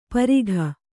♪ parigha